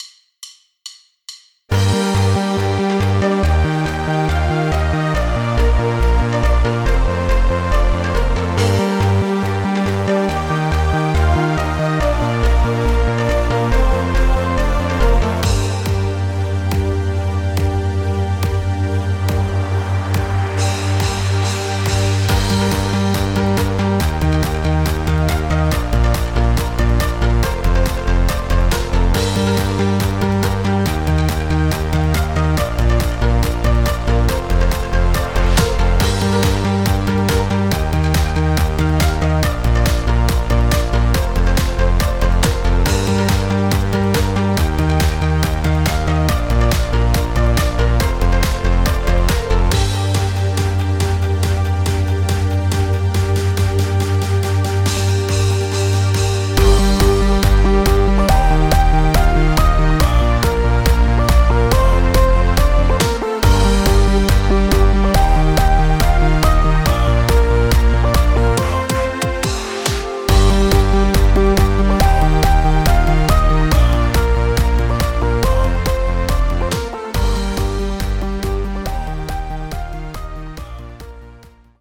Playback, Instrumental, Karaoke